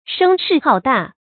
聲勢浩大 注音： ㄕㄥ ㄕㄧˋ ㄏㄠˋ ㄉㄚˋ 讀音讀法： 意思解釋： 浩：廣大。聲威和氣勢非常浩大。